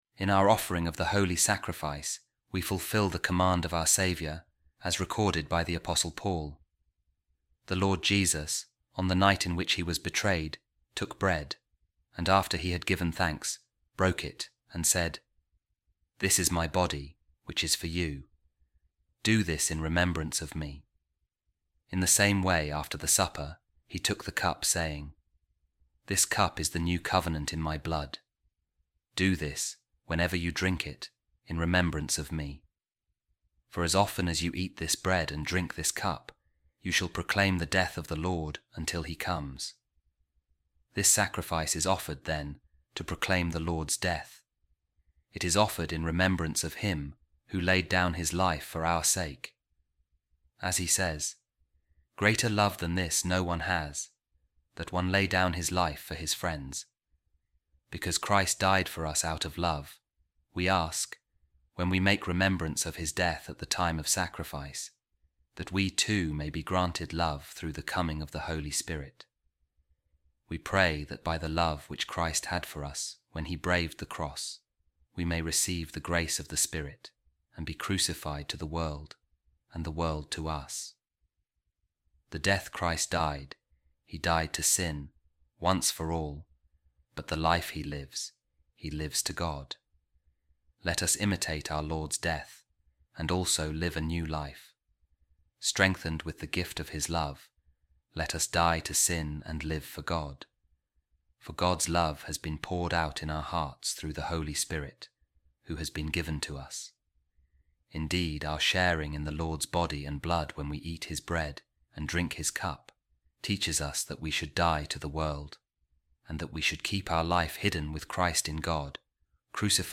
A Reading From The Treatise Of Saint Flugentius Of Ruspe Against Fabian | Sharing In The Body And Blood Of Jesus Christ Sanctifies Us